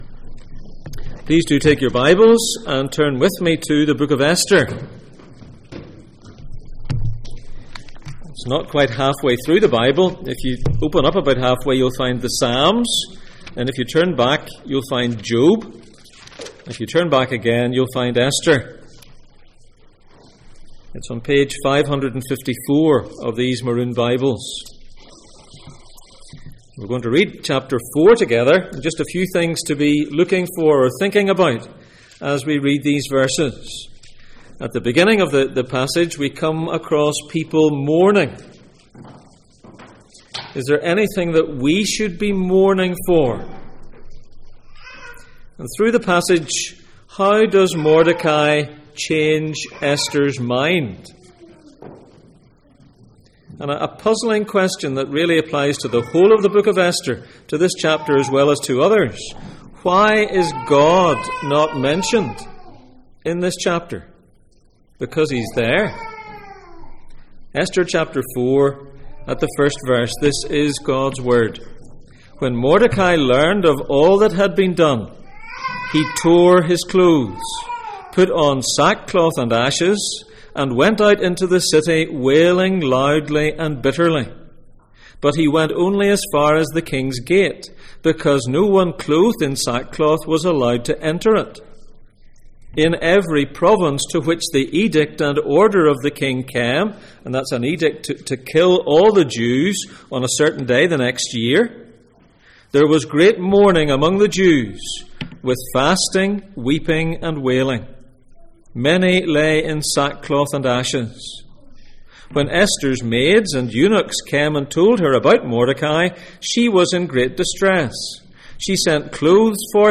Passage: Esther 4:1-17 Service Type: Sunday Morning